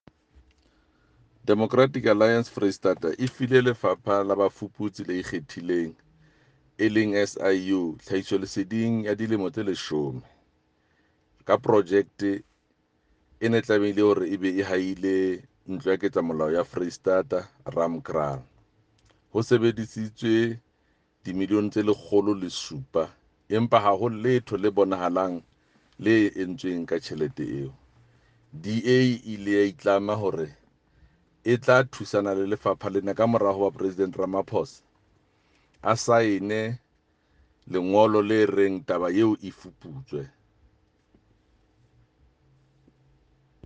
Sesotho soundbite by Jafta Mokoena MPL, as well as images here, here and here of a previous oversight.